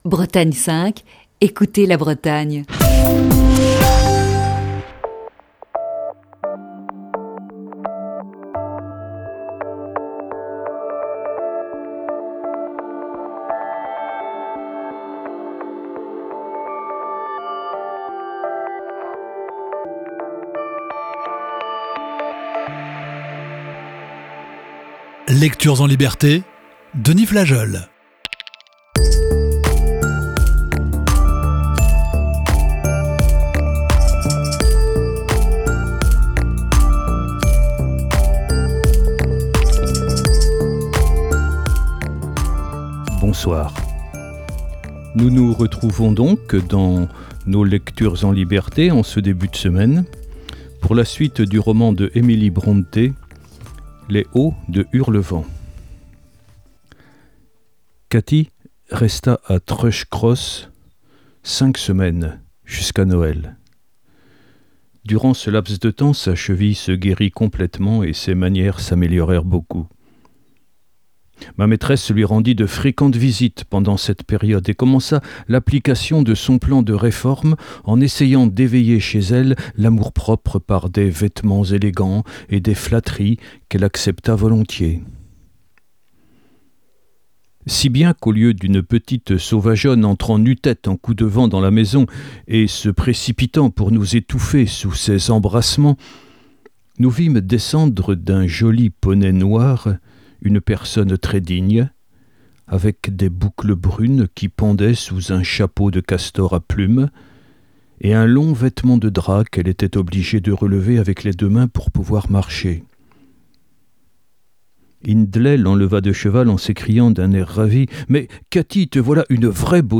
Voici ce lundi la lecture de la sixième partie de ce récit.